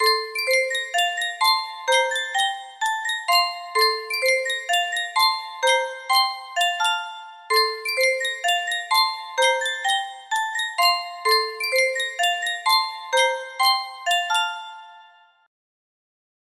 Sankyo Music Box - London Bridge is Falling Down DW music box melody
Full range 60